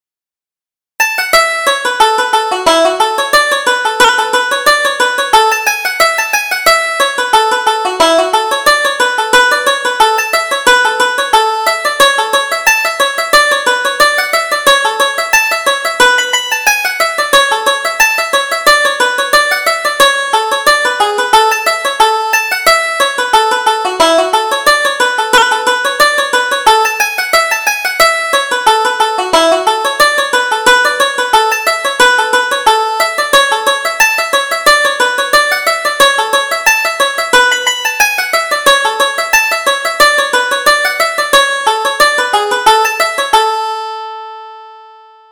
Reel: The Maple Tree